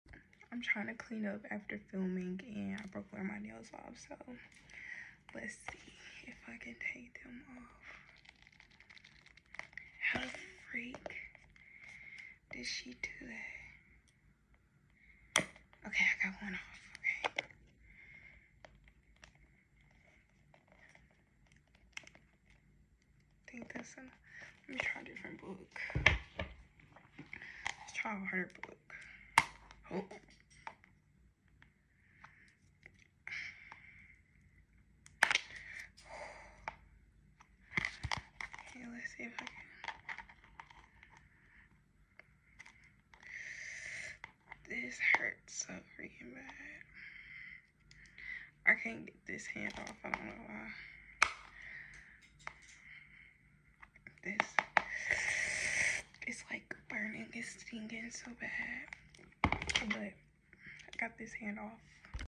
Nail Removal Asmr Using Books Sound Effects Free Download